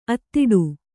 ♪ attiḍu